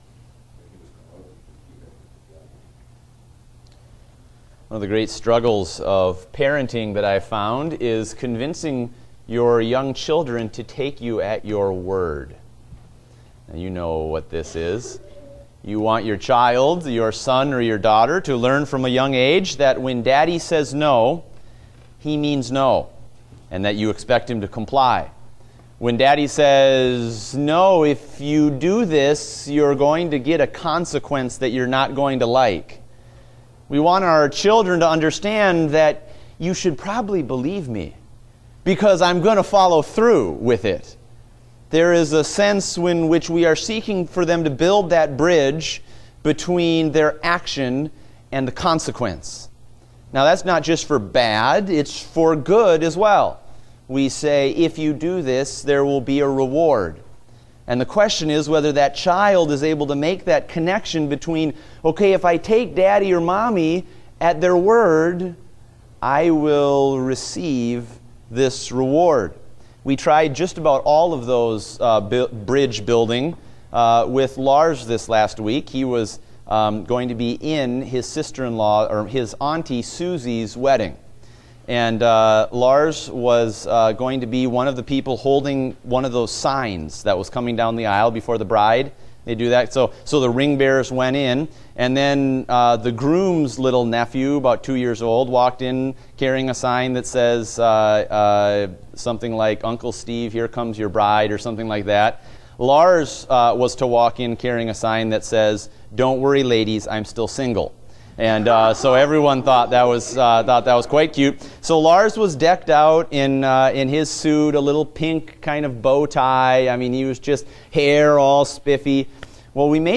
Date: July 10, 2016 (Adult Sunday School)